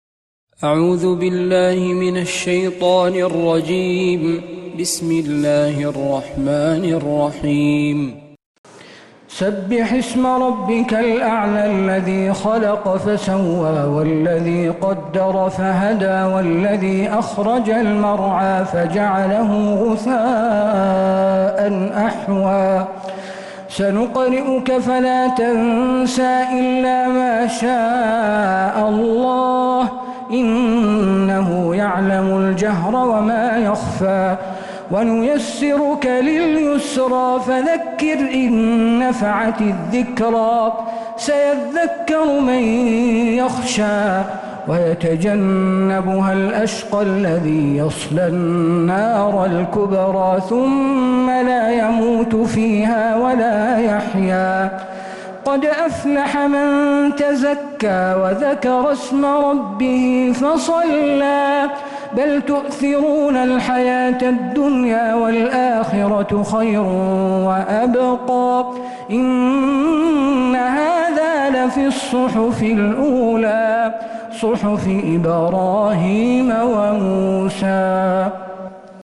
سورة الأعلى من تراويح الحرم النبوي